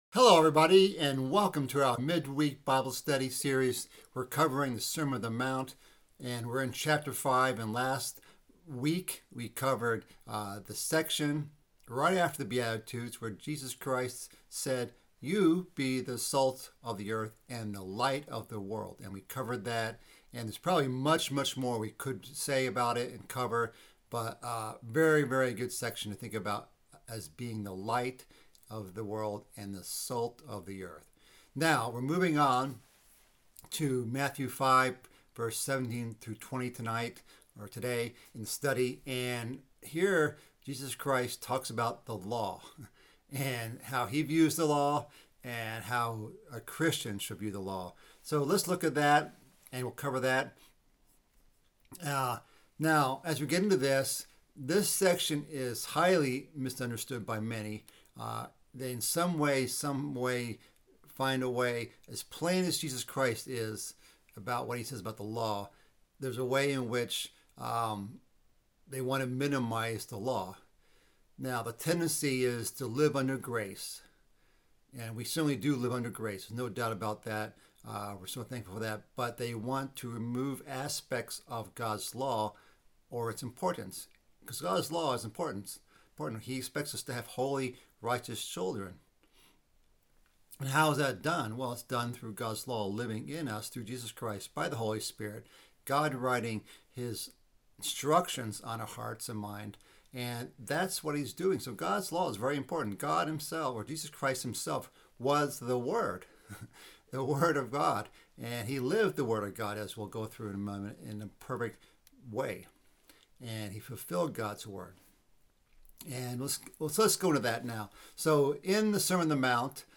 Mid-week Bible study following the sermon on the mount. This week covers the section about Christ coming not to destroy but to fulfill the law and the prophets.